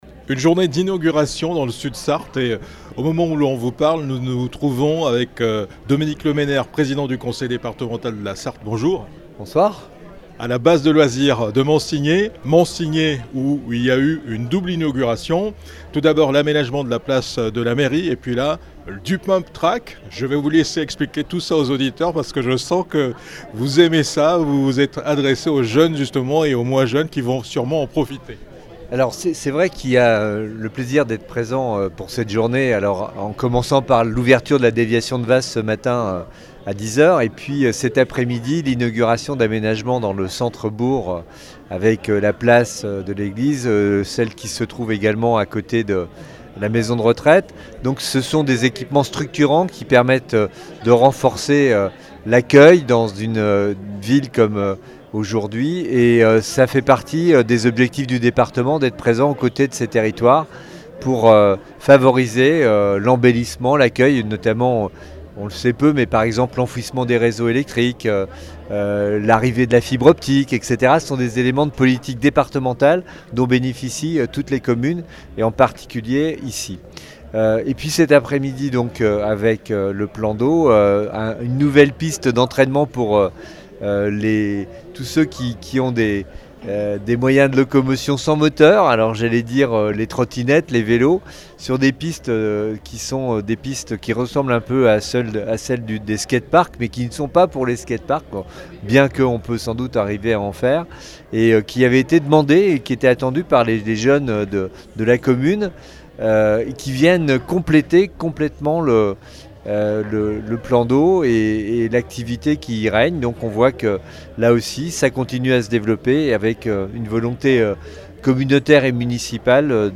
Cette journée a été marquée par deux cérémonies d'inaugurations. D'abord celle de l'aménagement de la Place de la Mairie, et ensuite, celle de de l'activité de Pumptrack sur la Base de loisirs.